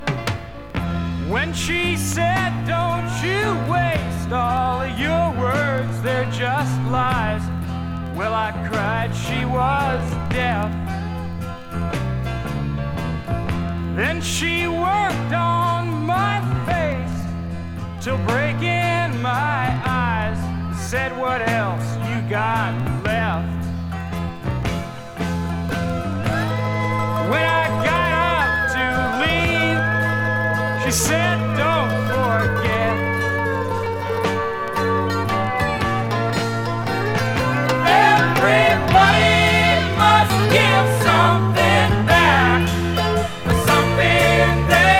Rock, Pop, Country Rock　USA　12inchレコード　33rpm　Stereo